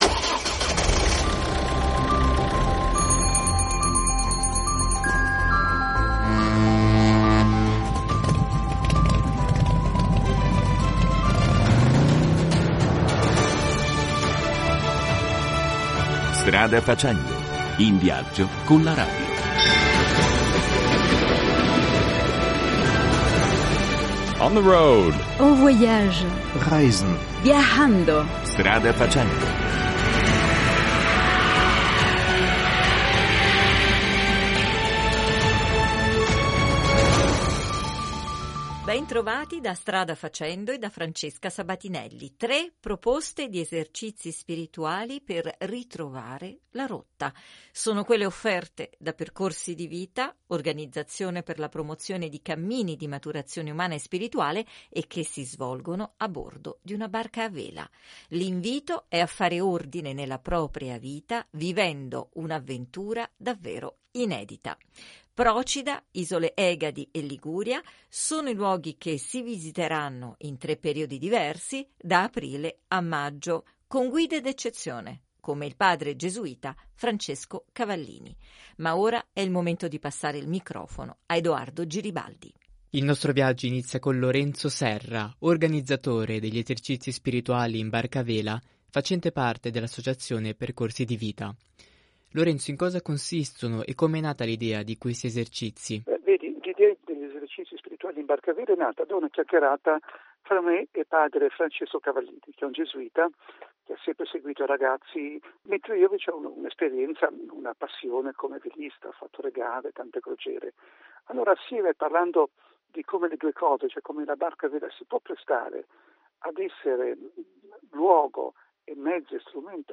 partecipante alle edizioni precedenti degli Esercizi spirituali .